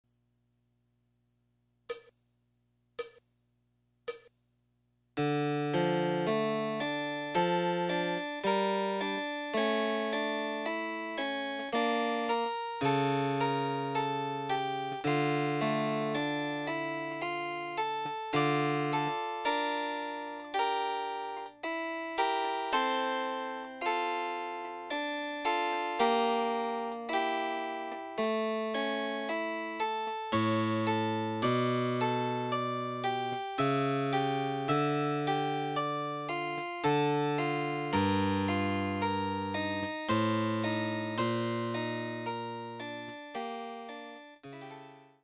★フルートの名曲をチェンバロ伴奏つきで演奏できる、「チェンバロ伴奏ＣＤつき楽譜」です。
試聴ファイル（伴奏）
デジタルサンプリング音源使用
※フルート奏者による演奏例は収録されていません。